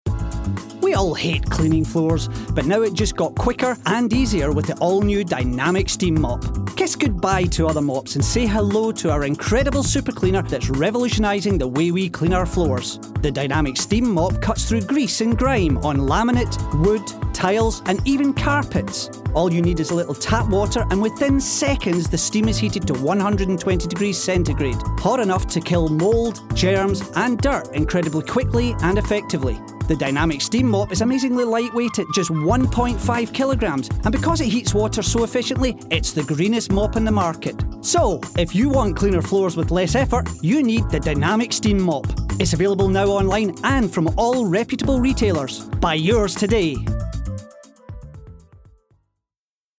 Male
English (Scottish)
Adult (30-50)
All our voice actors have professional broadcast quality recording studios.
0125Advertisement_VO.mp3